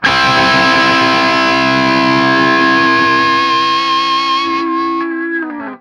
TRIAD C   -R.wav